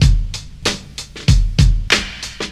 • 95 Bpm Drum Beat E Key.wav
Free breakbeat sample - kick tuned to the E note. Loudest frequency: 1150Hz
95-bpm-drum-beat-e-key-4Sw.wav